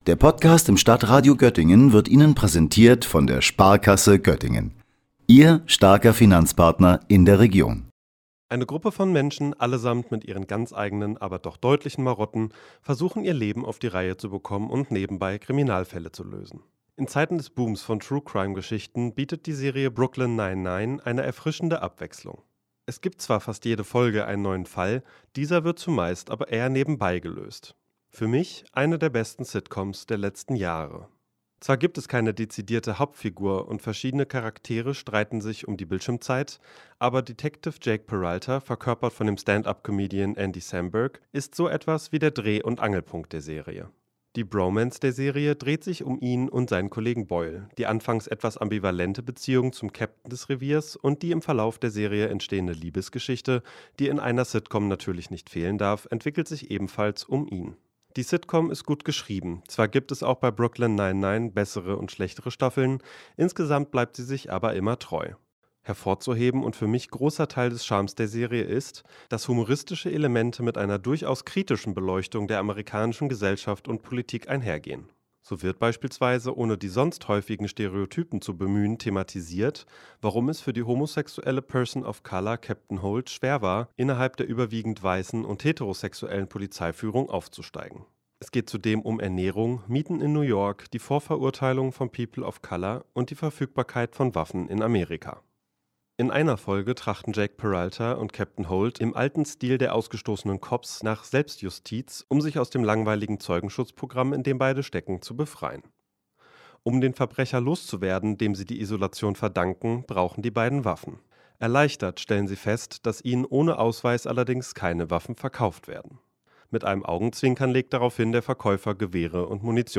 Beiträge > Rezension: Brooklyn Nine-Nine, die etwas andere Crime-Serie aus den USA - StadtRadio Göttingen